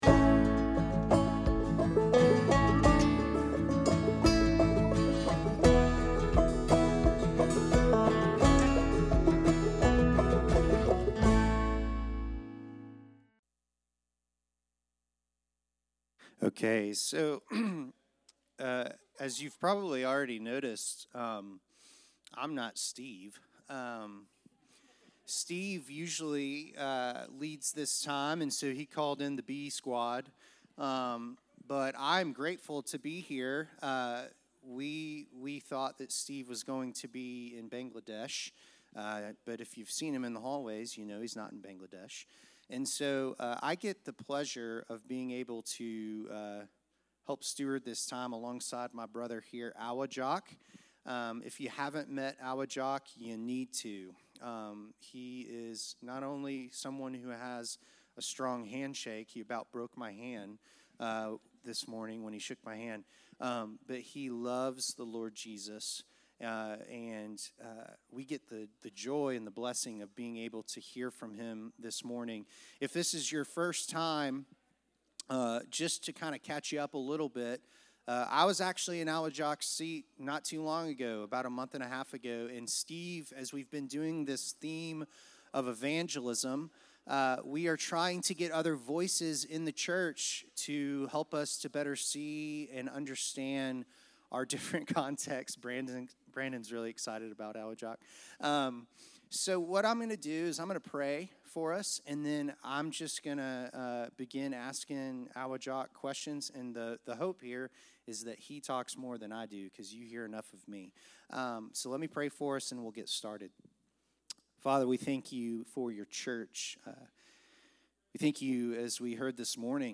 Sunday School: Evangelism interview - Christ Presbyterian Church